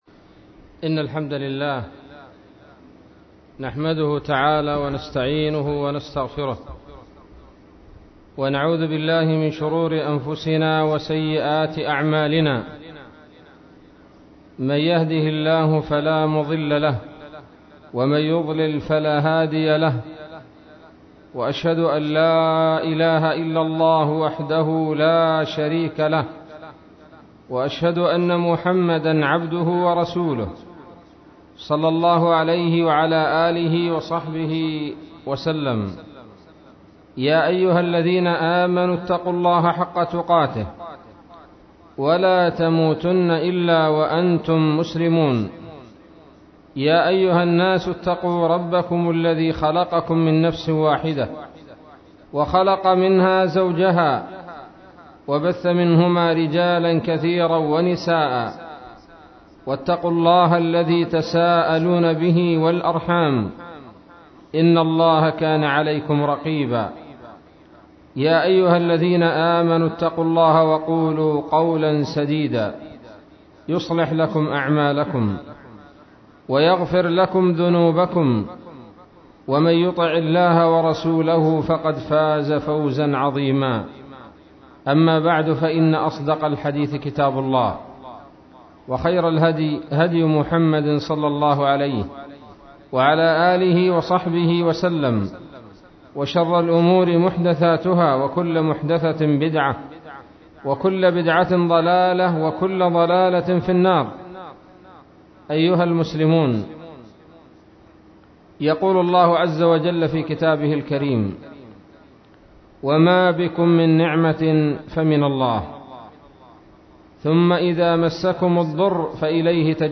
محاضرة بعنوان :((نعمة الأمن والأمان)) 5 ربيع الثاني 1437 هـ